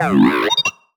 sci-fi_driod_robot_emote_22.wav